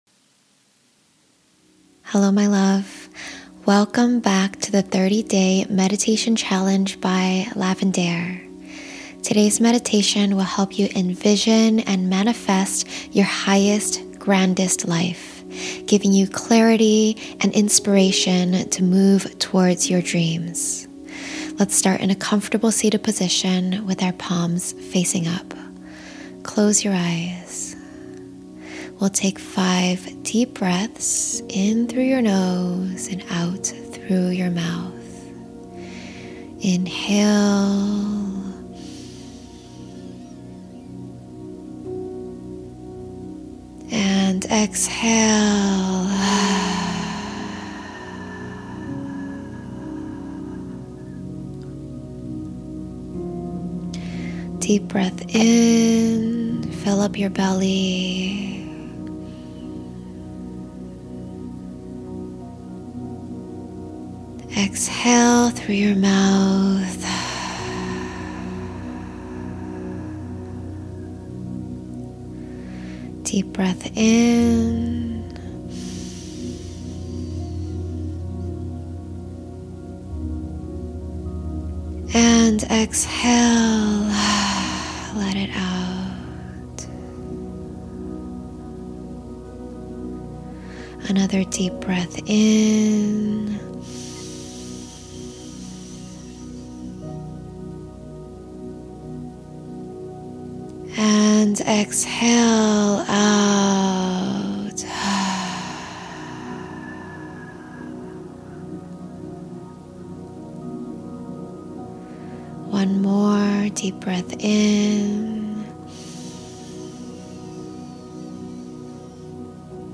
20 Minute Meditation to Manifest Your Dream Life (Powerful Visualization) | 30 Day Meditation Challenge